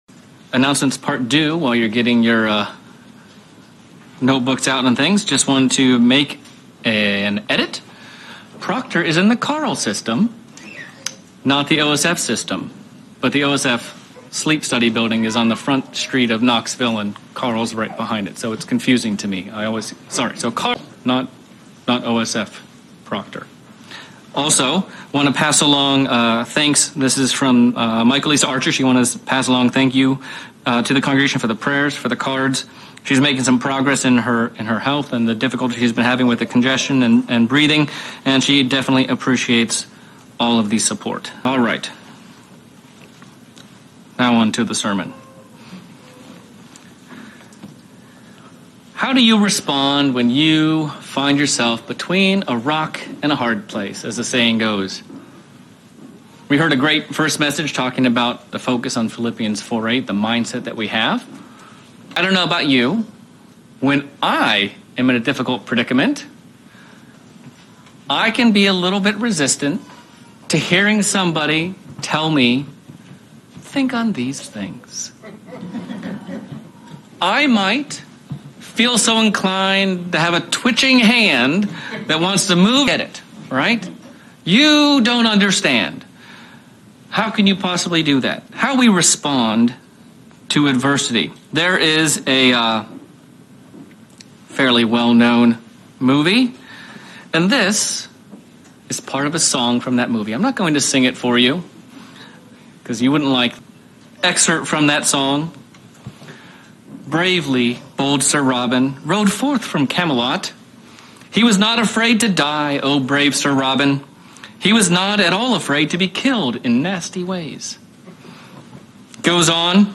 How do we respond to adversity and trial. This sermon looks at 3 ways God helps us respond in the correct manner.